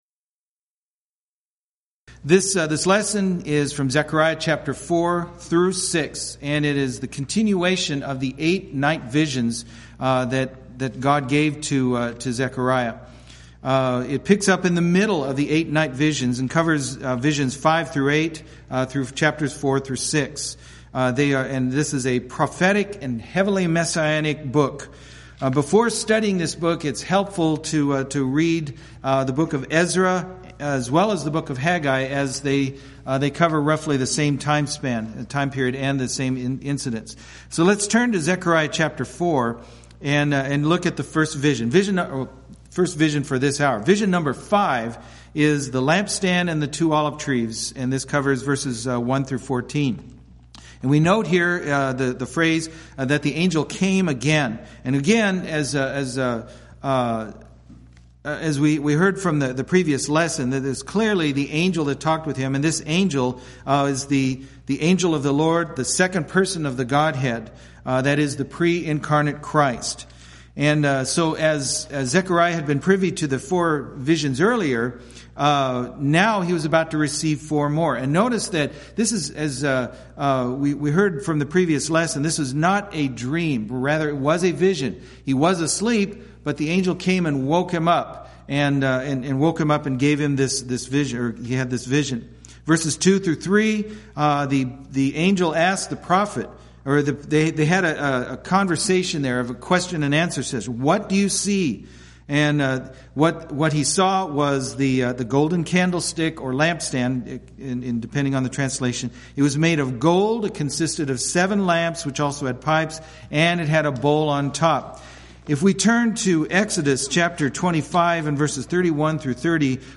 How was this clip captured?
Event: 12th Annual Schertz Lectures Theme/Title: Studies in the Minor Prophets